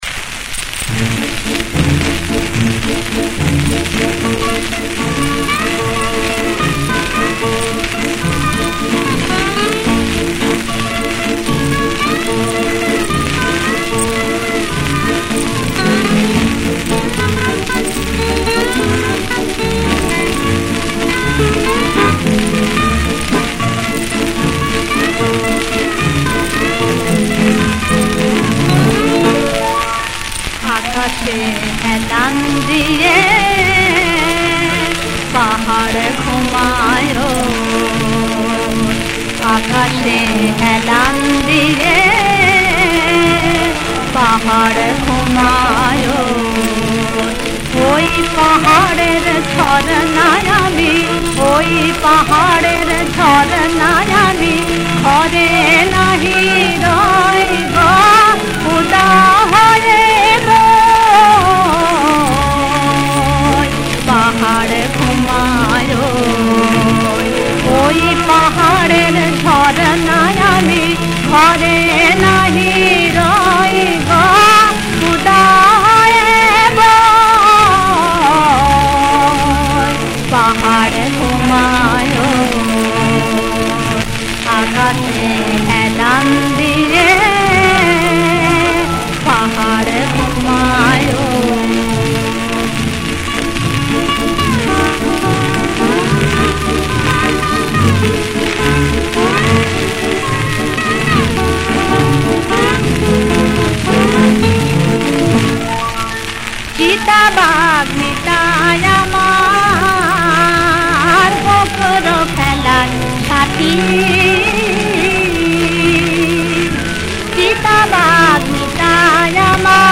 • তাল: দাদরা
• গ্রহস্বর: সা